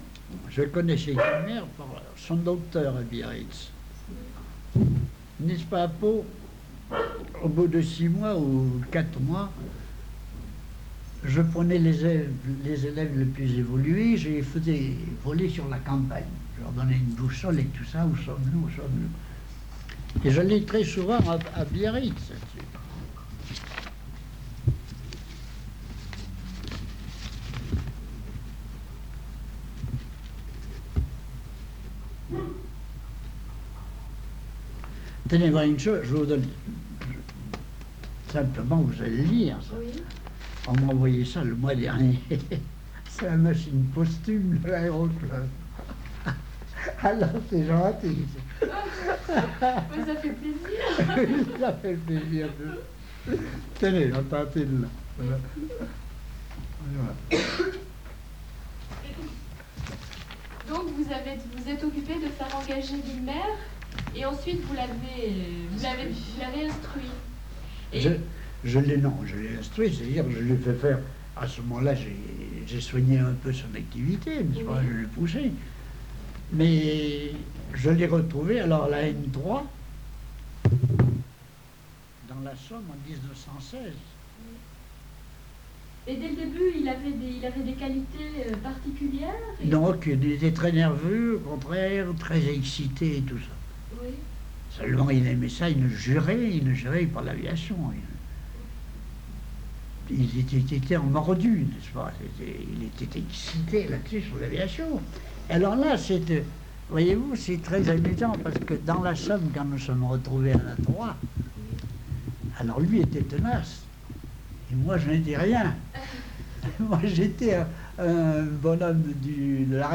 Entretien réalisé le 18 novembre 1975